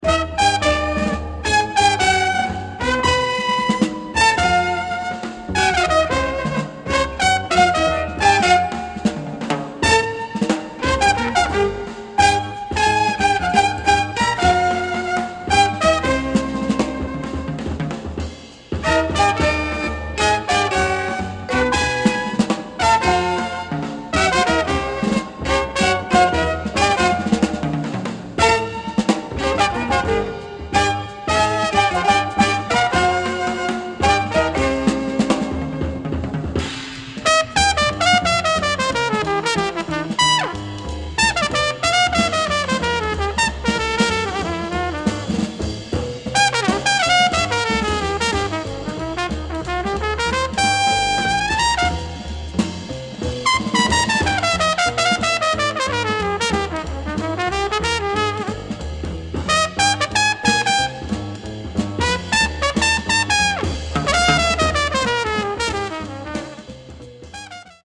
A full range of Jazz displayed here